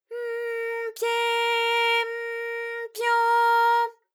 ALYS-DB-001-JPN - First Japanese UTAU vocal library of ALYS.
py_m_pye_m_pyo.wav